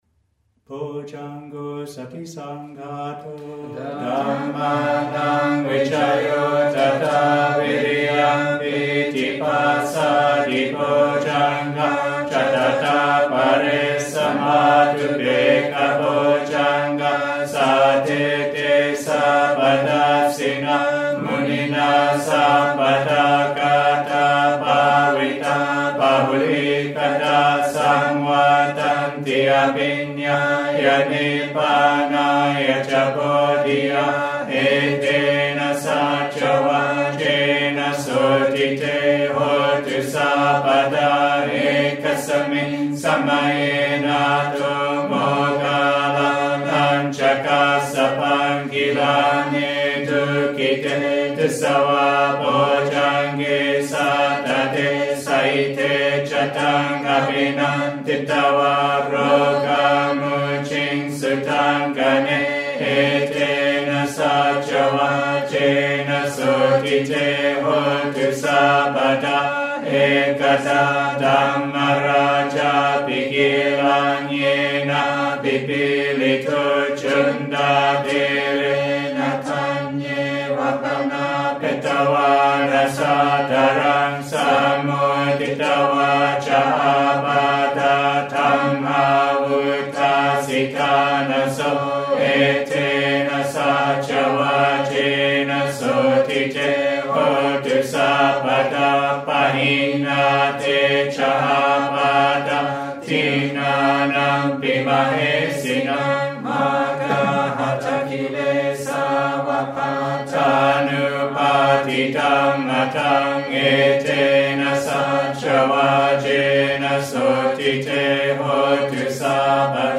» Pali-English Chanting